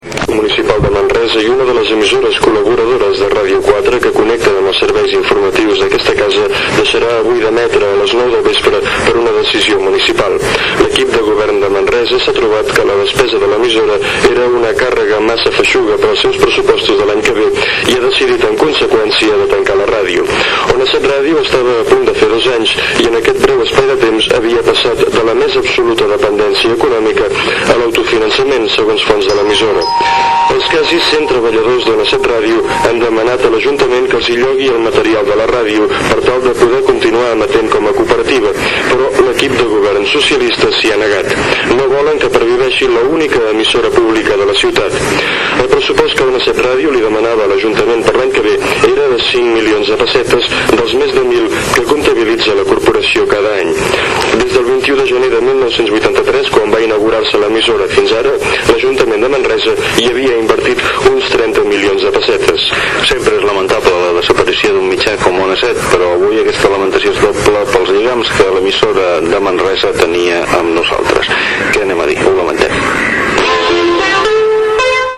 6f68ef0f3482e4b2af5983c6f625821cecc8b784.mp3 Títol Ràdio 4 Emissora Ràdio 4 Cadena RNE Titularitat Pública estatal Descripció Tancament avui d'Ona 7 Ràdio, emissora municipal de Manresa. Gènere radiofònic Informatiu